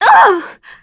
Juno-Vox_Damage_kr_03.wav